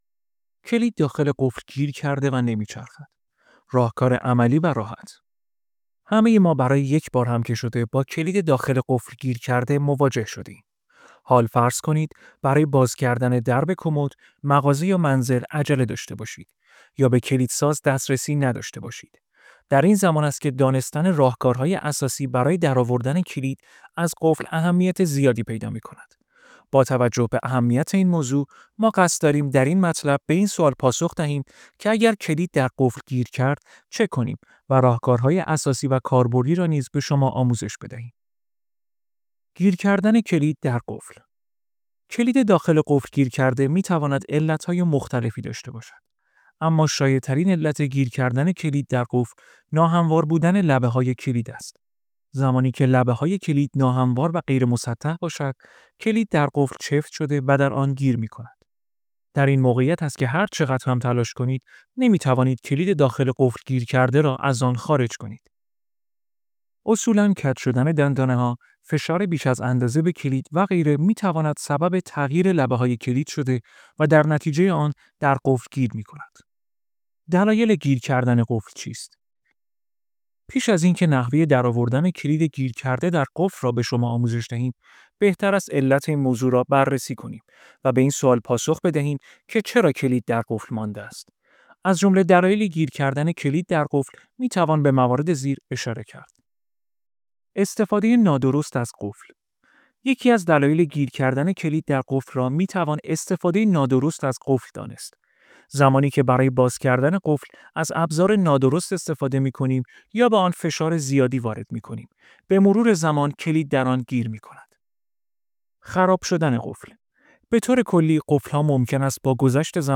پادکست کلید داخل قفل گیر کرده و نمی چرخد | راهکار عملی و راحت